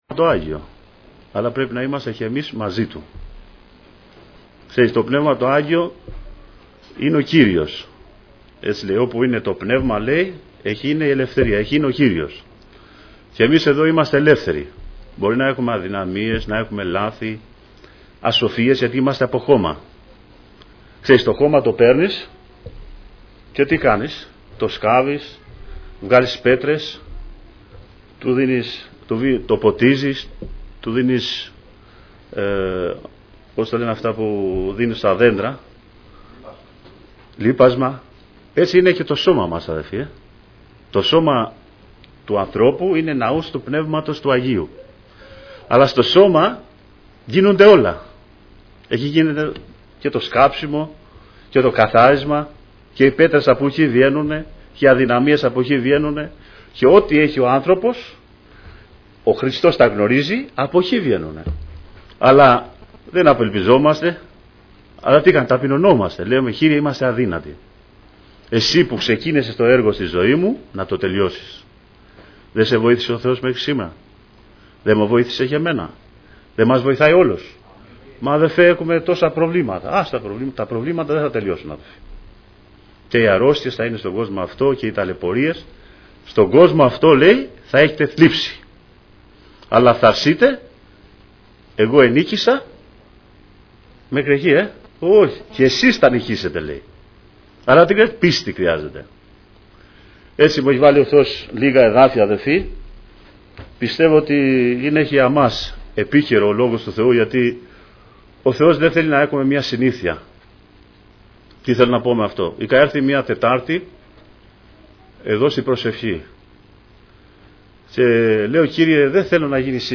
Ομιλητής: Διάφοροι Ομιλητές Λεπτομέρειες Σειρά: Κηρύγματα Ημερομηνία: Κυριακή, 18 Οκτωβρίου 2015 Εμφανίσεις: 241 Γραφή: Λουκάς 8:40-8:56; Λουκάς 17:11-17:19 Λήψη ήχου Λήψη βίντεο